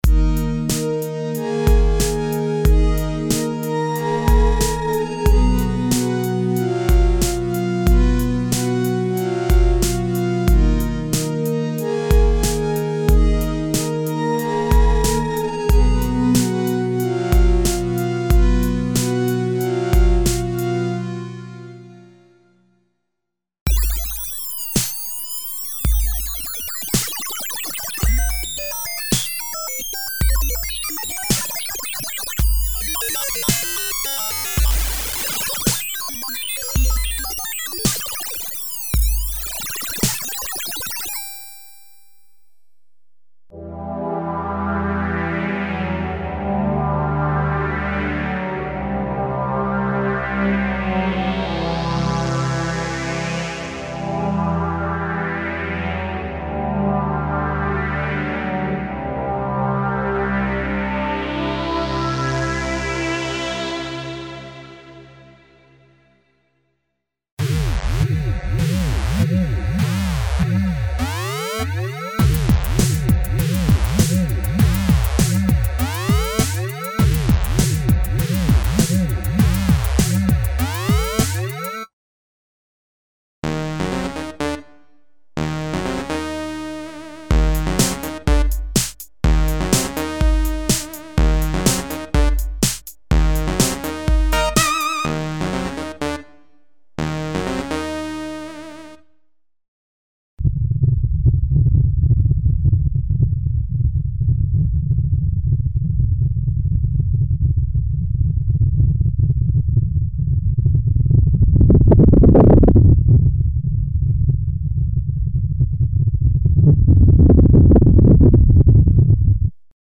Emulation of vintage analog & digital synthesizers (warm analog pads and sweeps, poly synths, experimental and "ice-cold" digital pads, synth basses, mono leads, seq. programs, filtered noises, synth effects, etc.).
Free Kurzweil K2xxx ROM & FARM sound programs: 320 Info: All original K:Works sound programs use internal Kurzweil K2500 ROM samples exclusively, there are no external samples used.